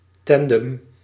Ääntäminen
Ääntäminen Tuntematon aksentti: IPA: /ˈtɑn.dɛm/ Haettu sana löytyi näillä lähdekielillä: hollanti Käännös Ääninäyte Substantiivit 1. tandem 2. pair US 3. couple US UK 4. duo Suku: m .